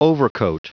Prononciation du mot overcoat en anglais (fichier audio)